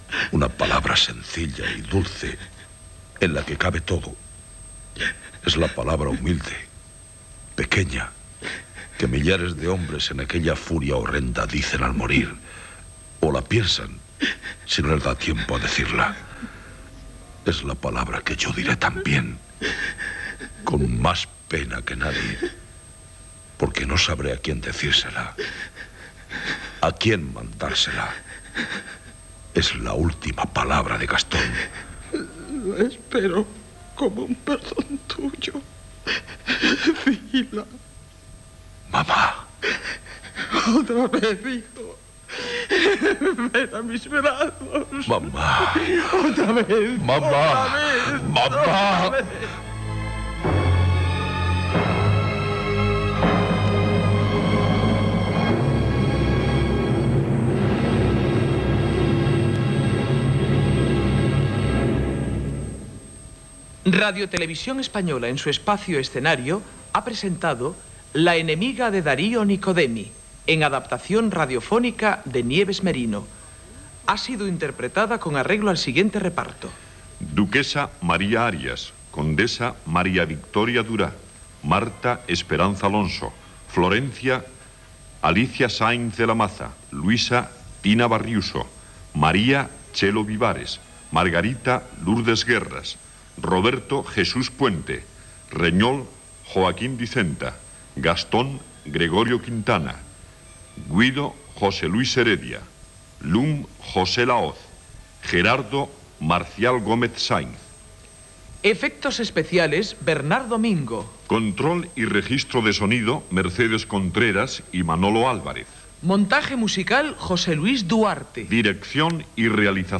Fragment del drama final de l'obra "La enemiga" de Dario Niccodemi en veu de l'actor Jesús Puente. Crèdits del repartiment i música final.